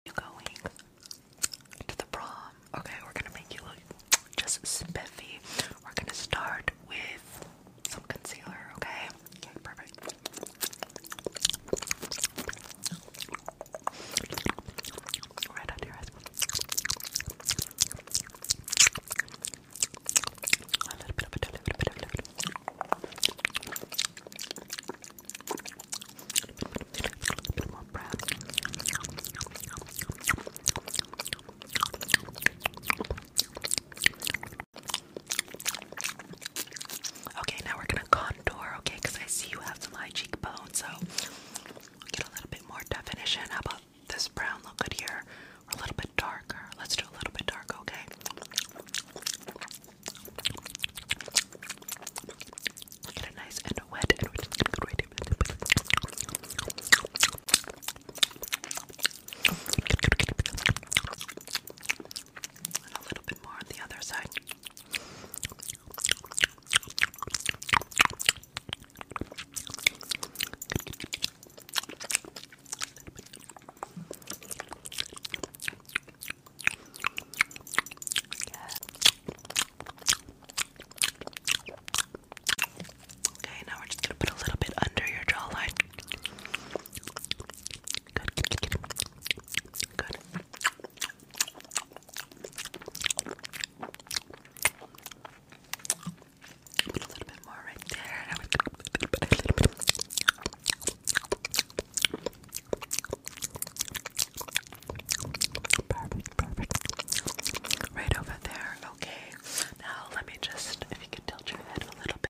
Spit Painting on your Makeup sound effects free download